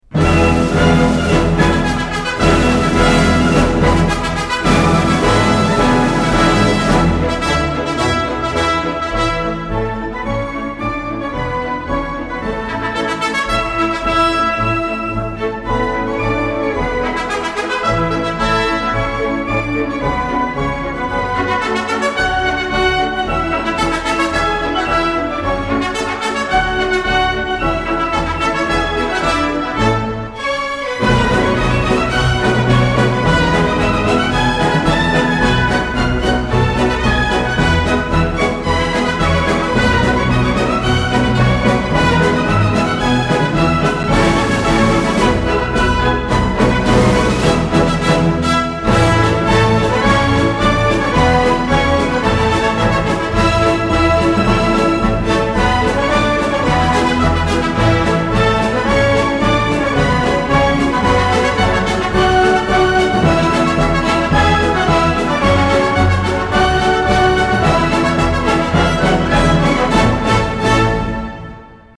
L'hymne monégasque par l'OPMC (1.1 Mo)